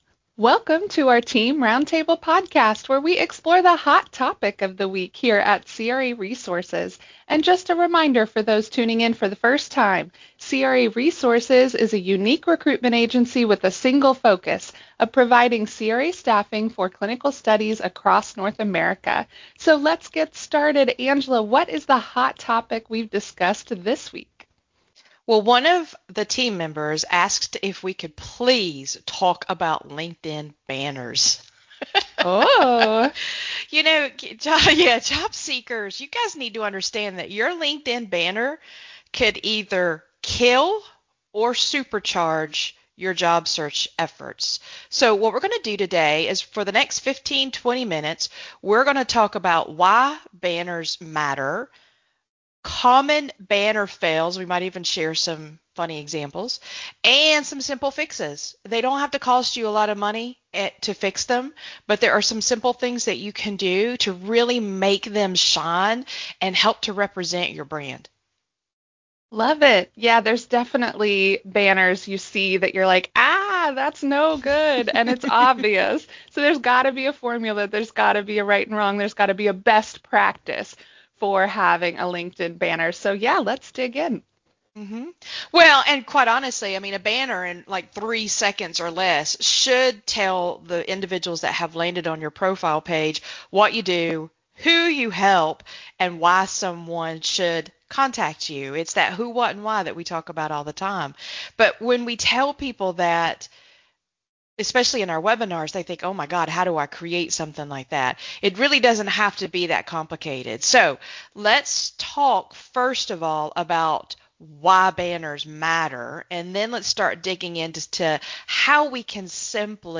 Today’s roundtable is about avoiding banner disasters that send great candidates straight to the “nope” pile.